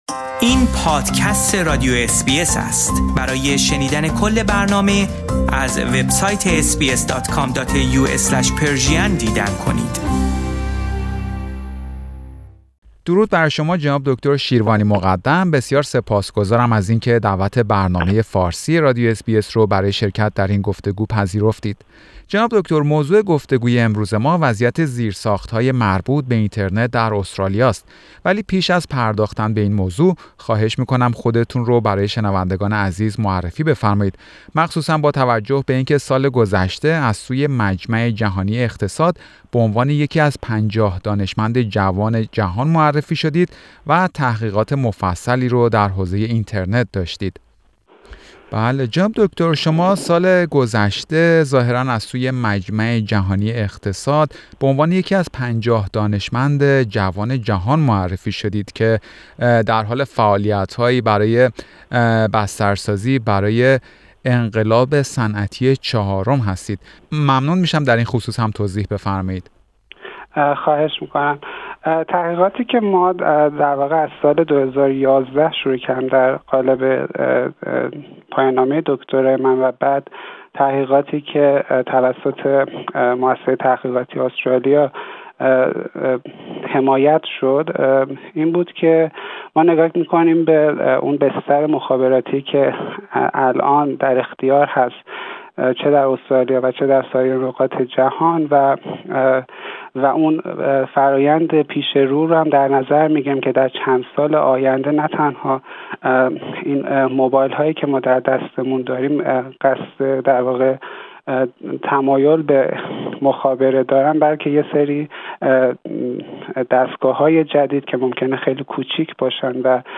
بررسی وضعیت و چشم انداز اینترنت استرالیا در گفتگو با دانشمند ایرانی مقیم سیدنی